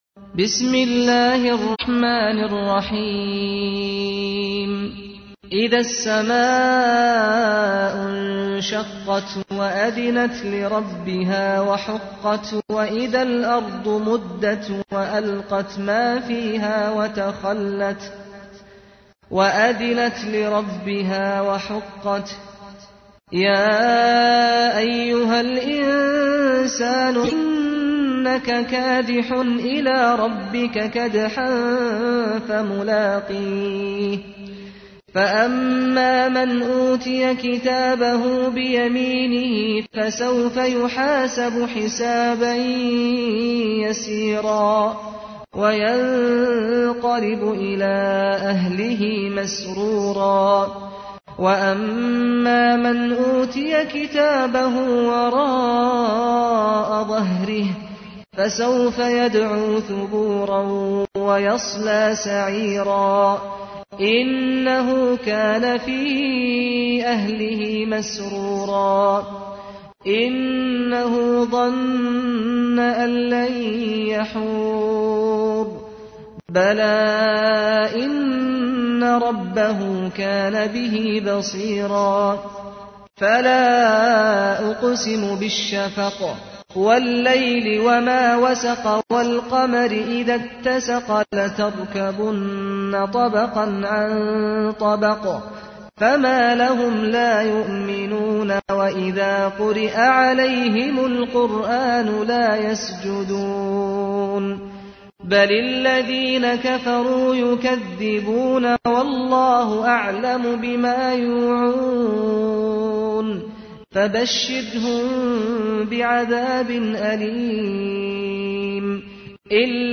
تحميل : 84. سورة الانشقاق / القارئ سعد الغامدي / القرآن الكريم / موقع يا حسين